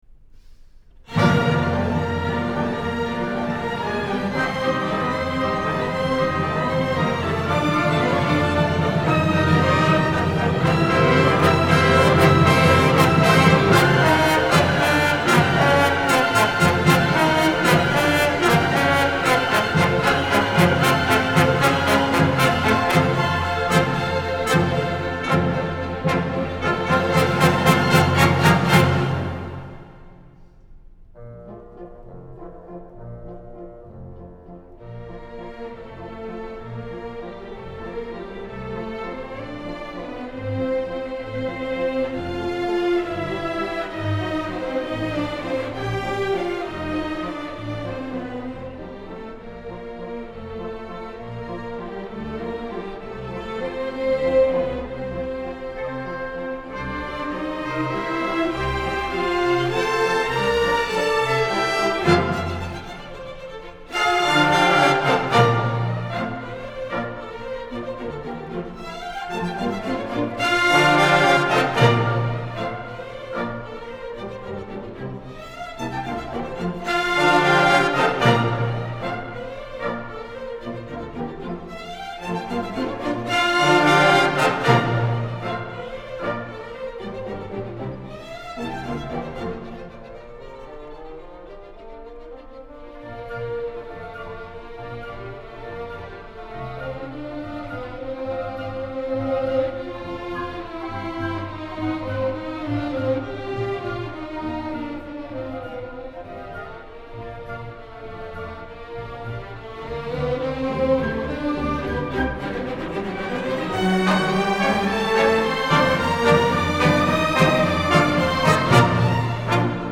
������ Concert